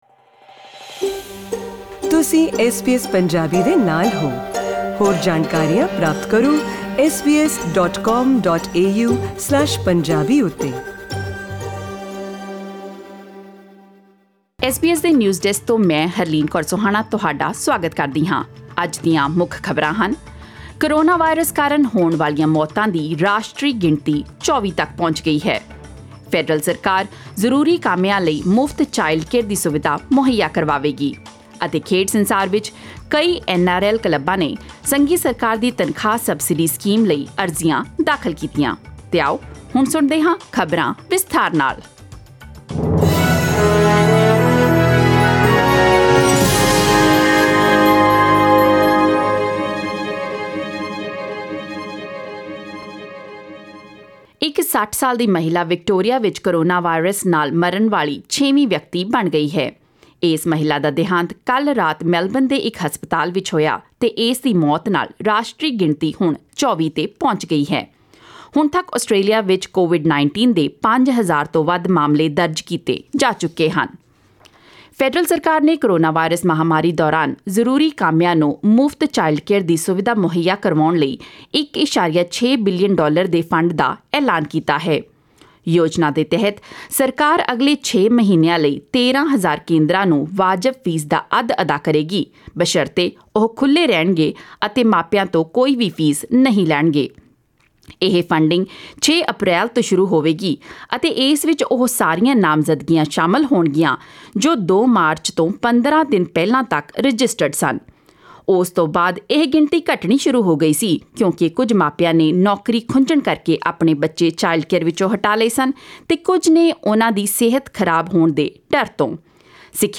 In today’s news bulletin: - The national coronavirus death toll rises to 24...